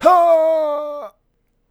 death1.wav